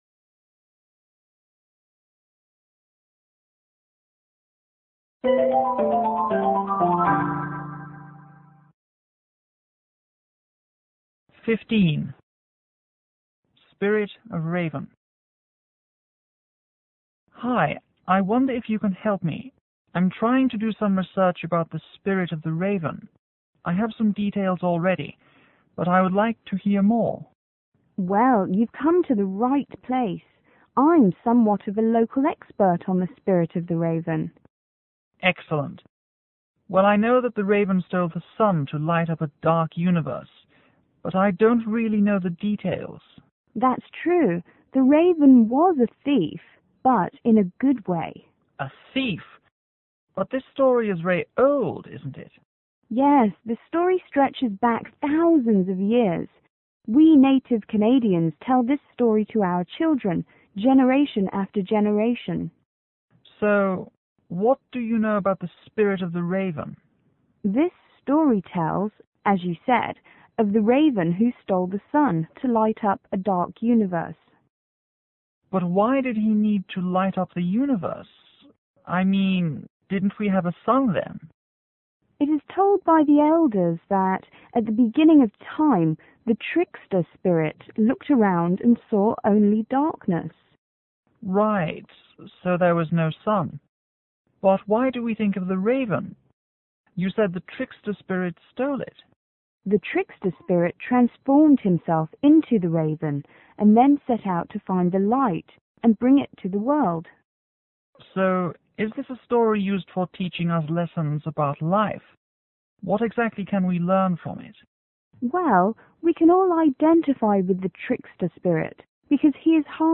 L: Local    V: Visitor